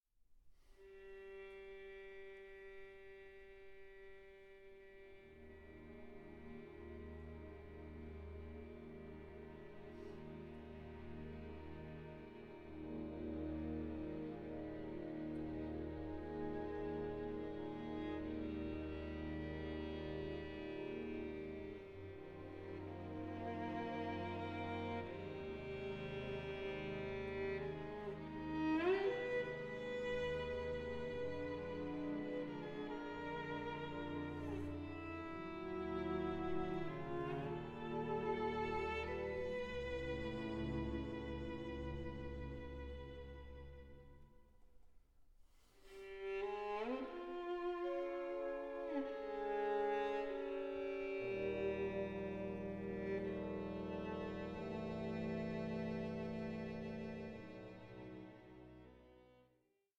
Lamenting 8:56